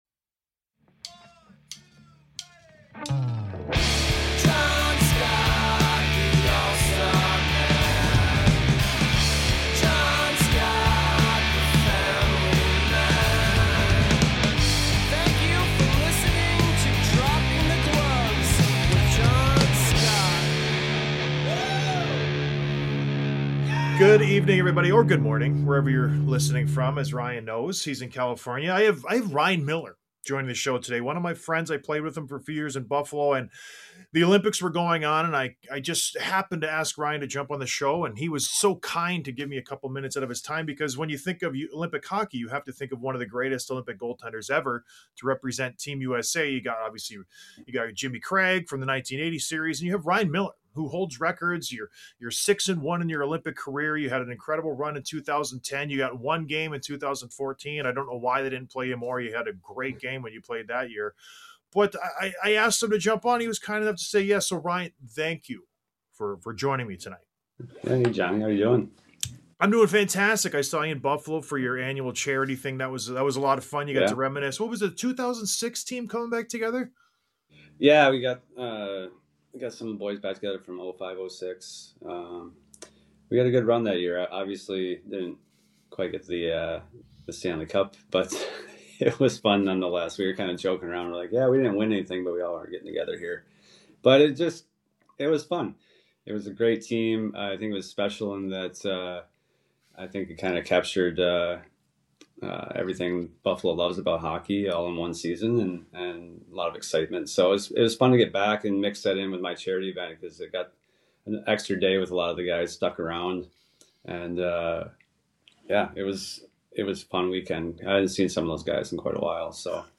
Interview w/ Olympic Legend Ryan Miller